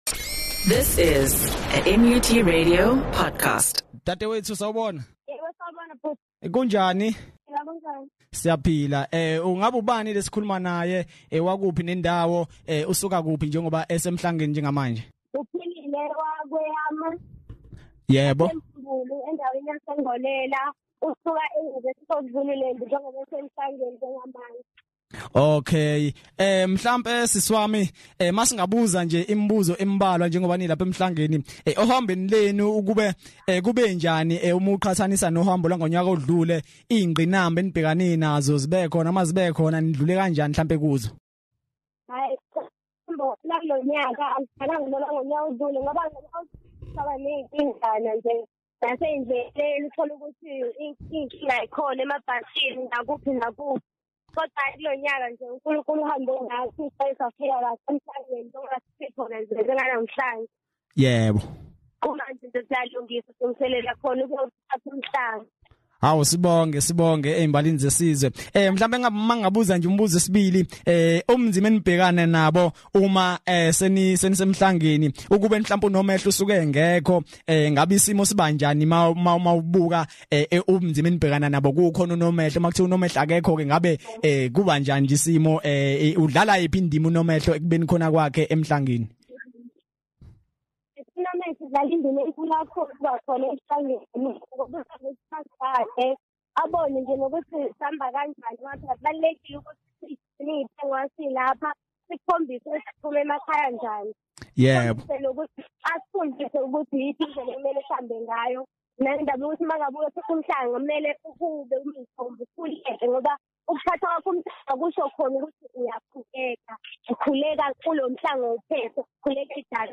interview
phone interview